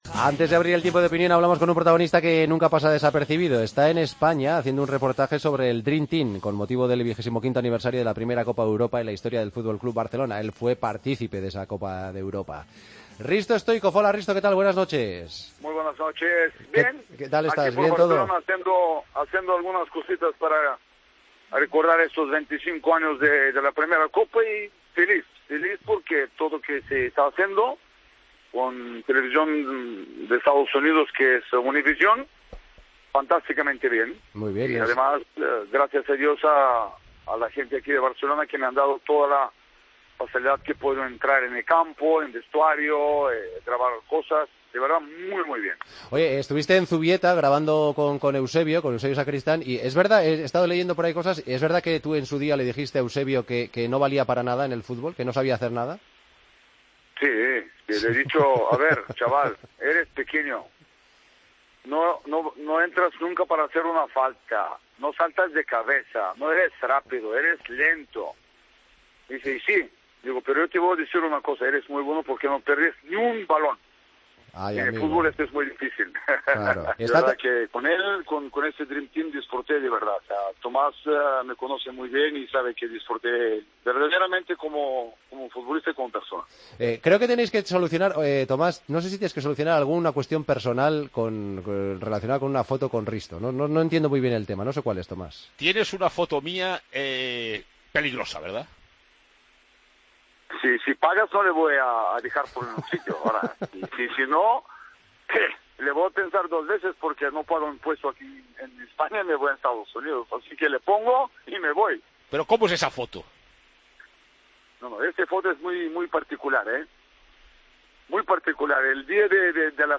Hablamos con el exjugador búlgaro, durante su estancia en España